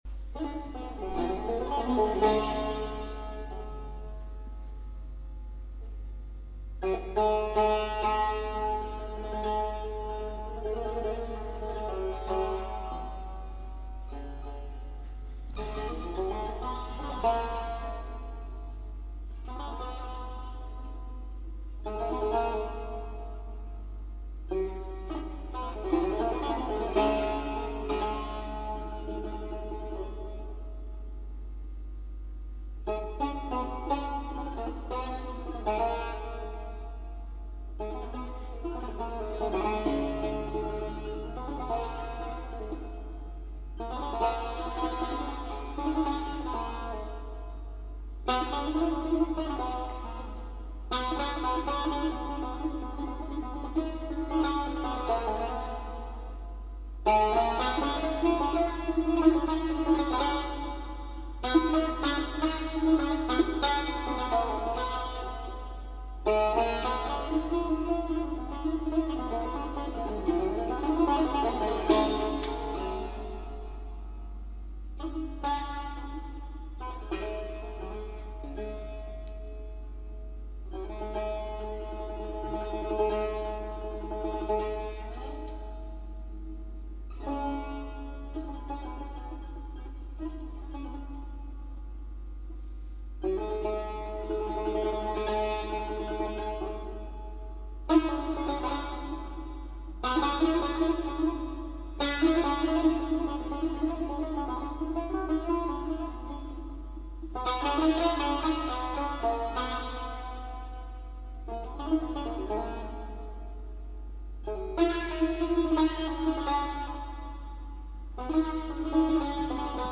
taar.mp3